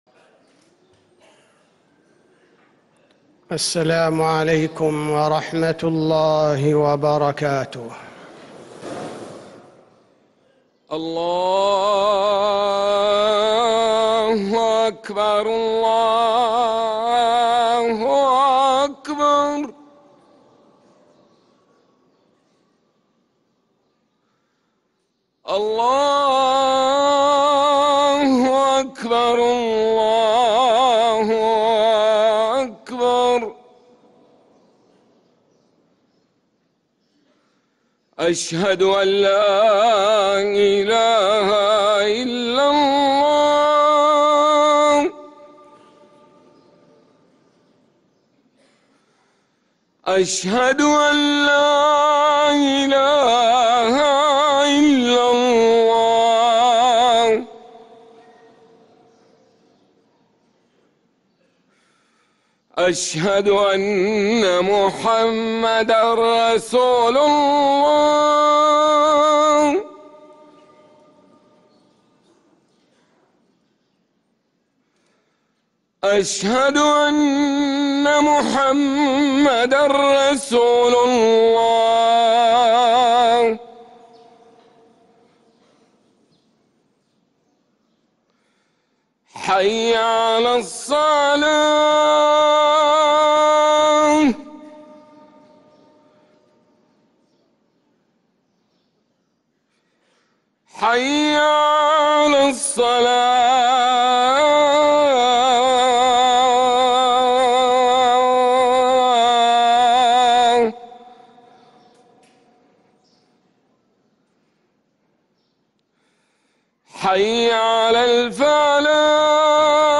أذان الجمعة الثاني للمؤذن